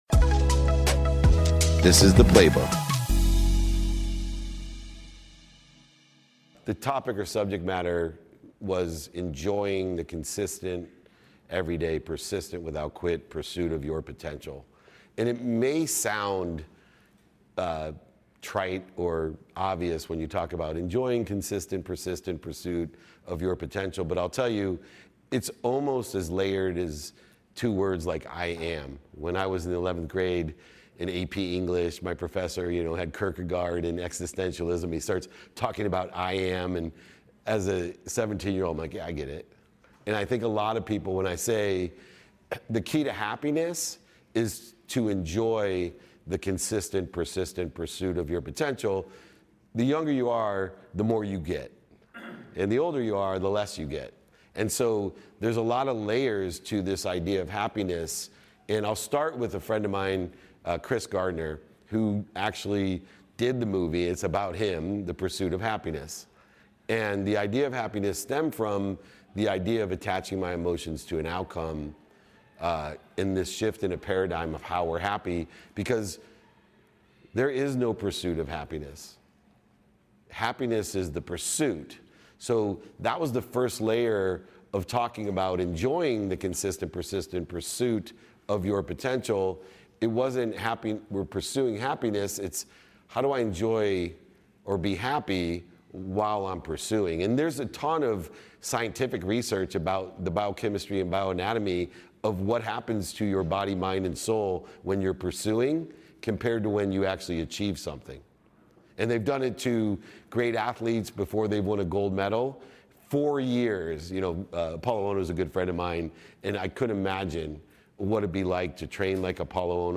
In his keynote at the VeeCon Visa Creator Stage